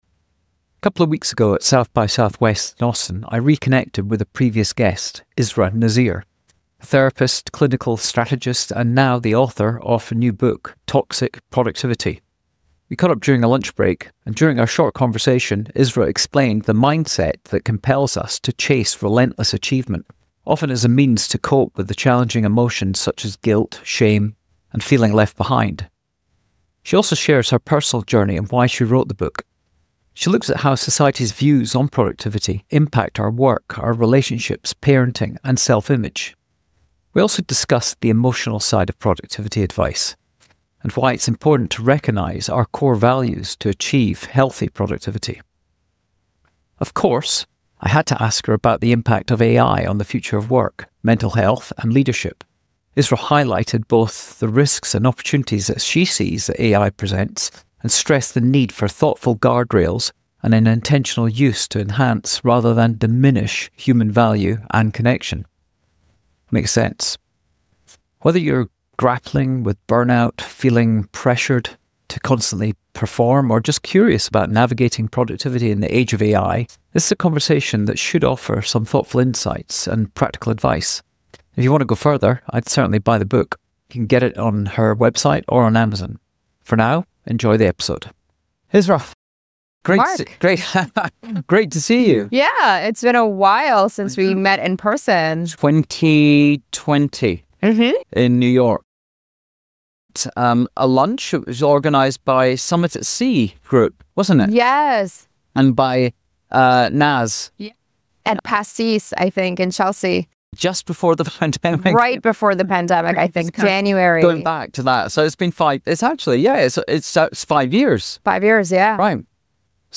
at SXSW 2025